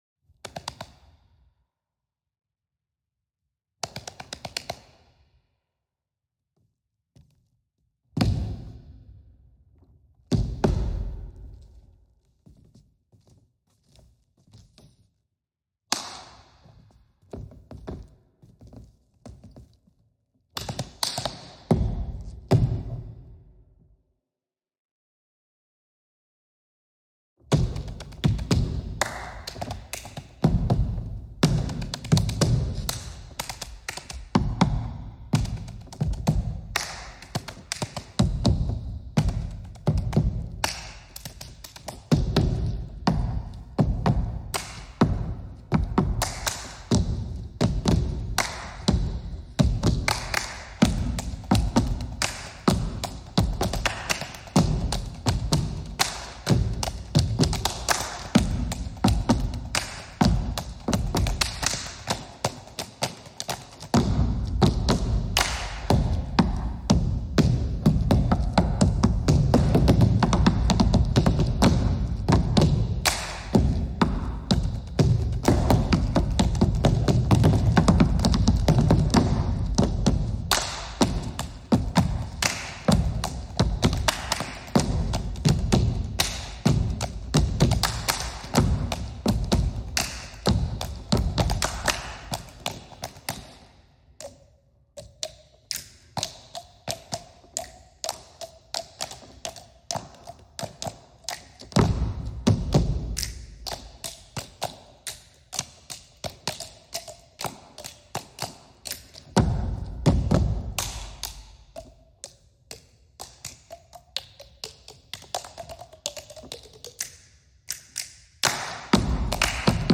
quartet for body percussion
Voicing: Percussion Quartet